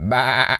pgs/Assets/Audio/Animal_Impersonations/sheep_baa_bleat_01.wav at master
sheep_baa_bleat_01.wav